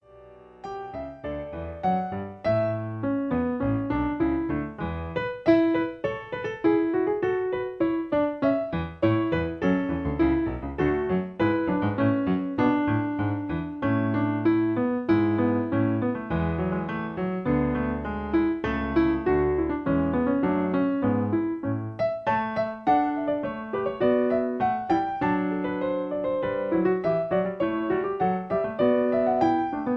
Original Key (D). Piano Accompaniment